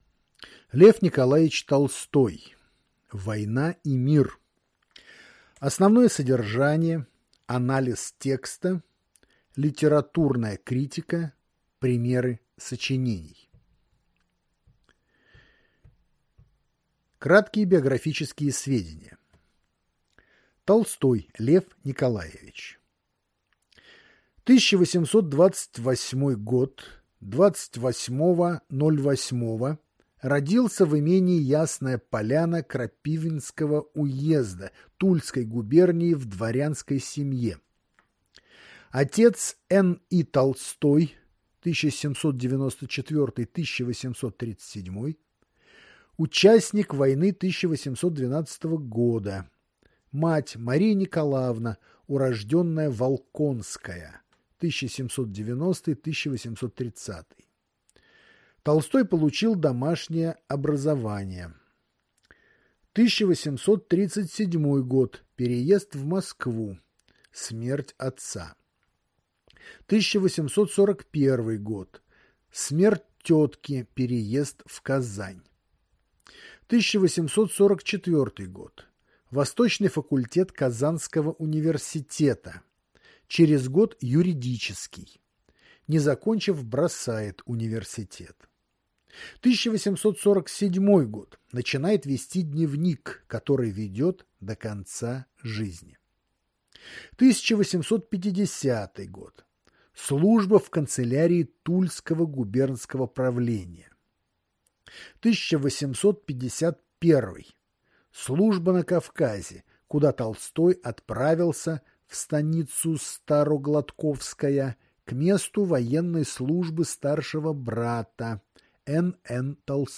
Аудиокнига Л. Н. Толстой «Война и мир». Краткое содержание.